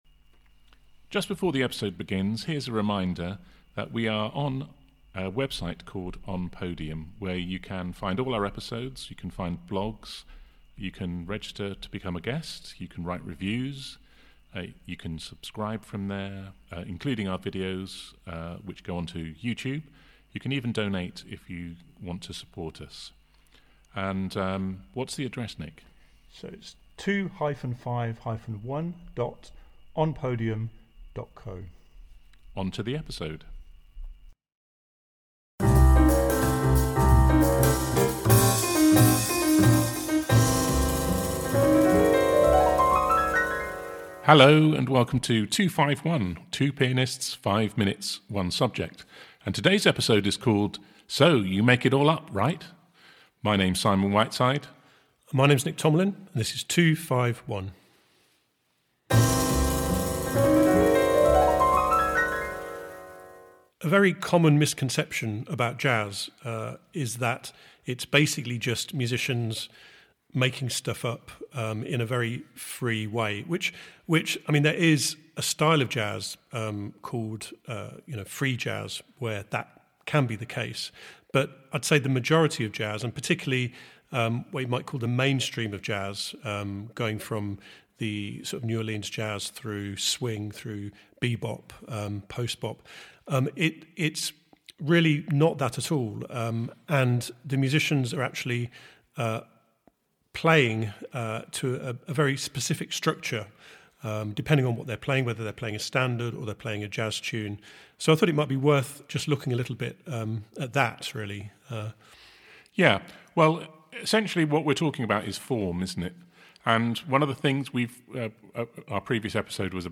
We actually made this episode up off-the-cuff....How very meta!